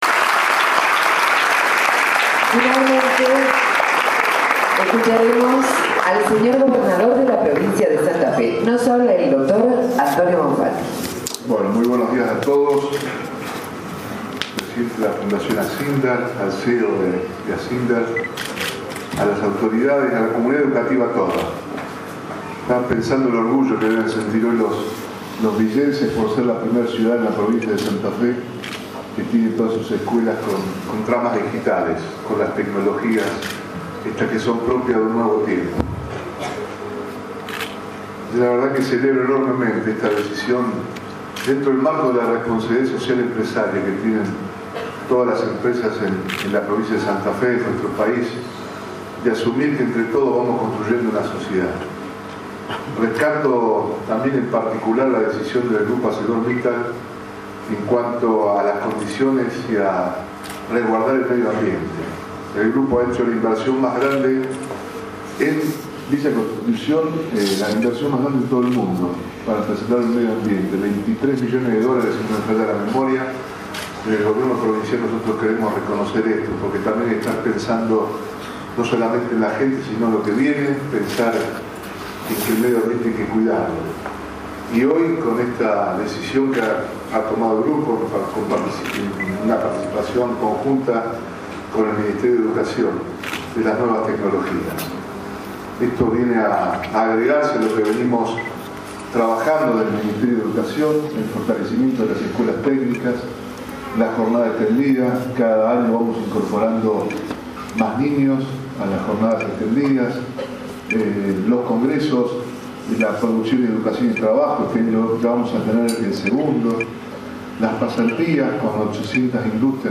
Esto sucedió en la Escuela N° 6216 “Combate de la Vuelta de Obligado” , Villa Constitución, Pcia. de Santa Fe.
Gobernador de la Prov. Santa Fe  Antonio Bonfatti